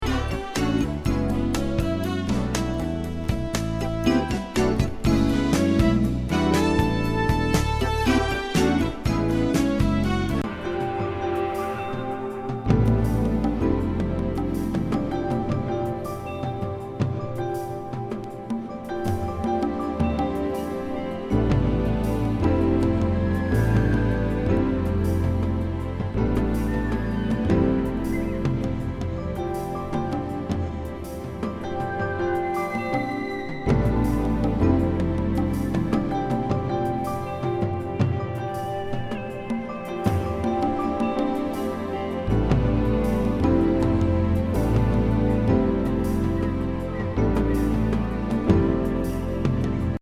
極上メロウ
＆スムースな